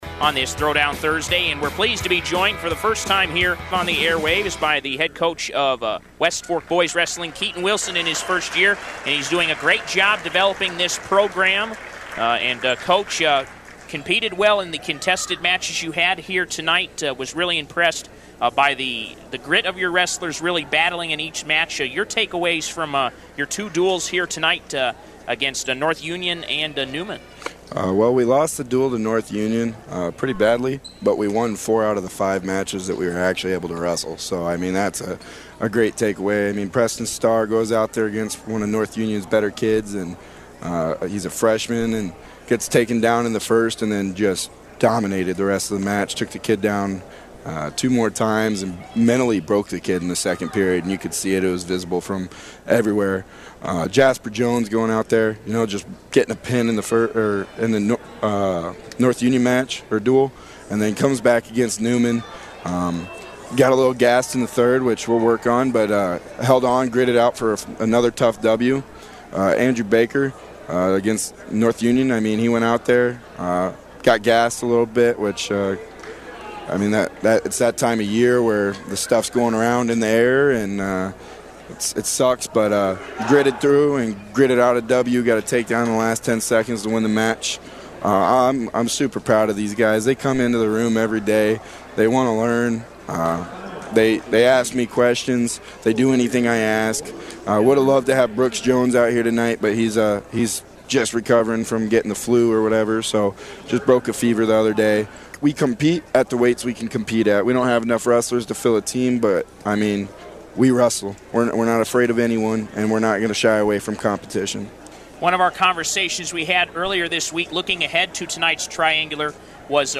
Postgame Interview: